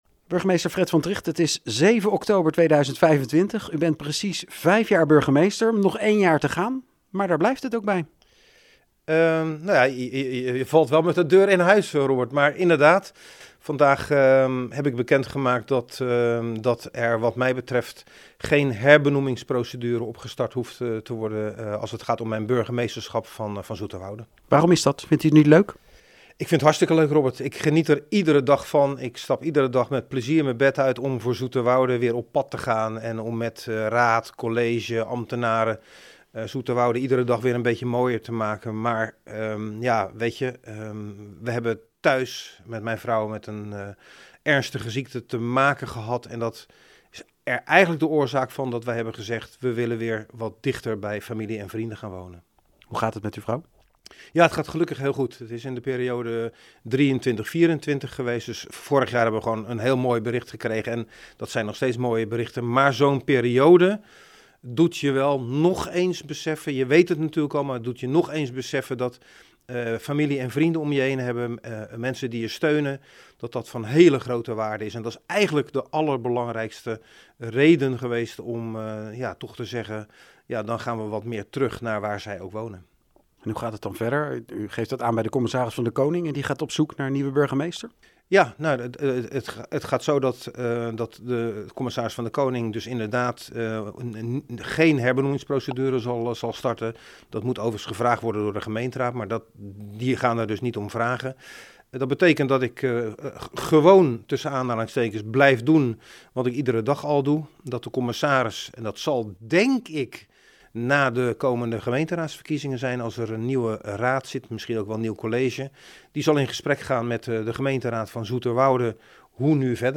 in gesprek met burgemeester Fred van Trigt over zijn besluit om in 2026 te stoppen.